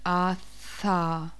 Pulmonische Konsonantensymbole
Es ertönt der Konsonant in [a_a]-Umgebung.